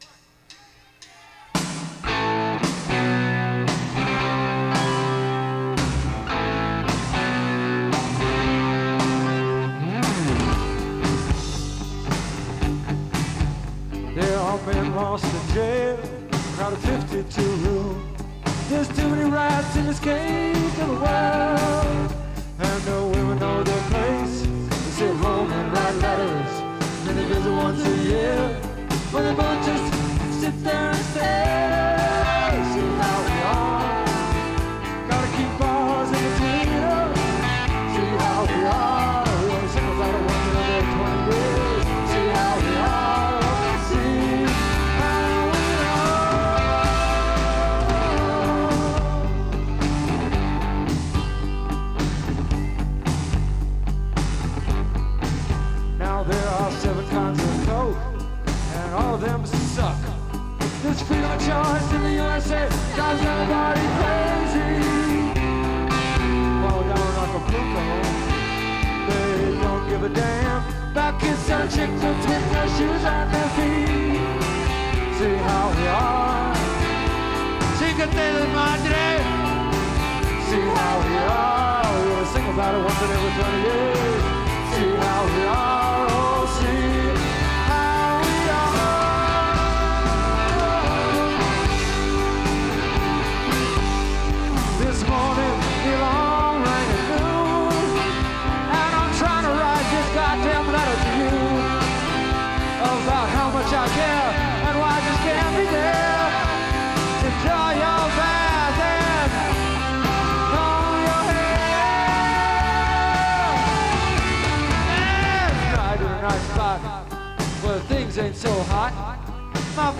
Live from the Cabaret in July 1986.